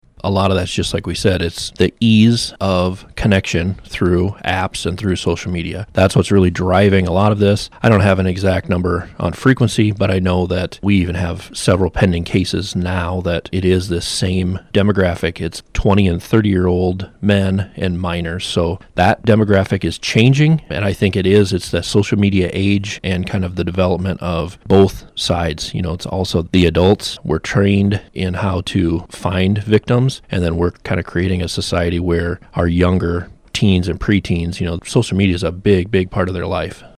Franklin County Sheriff Aaron Dodd tells RadioOnTheGo News these instances are becoming more of a problem locally.